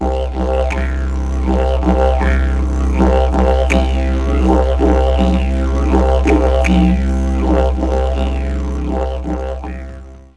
didjerid.wav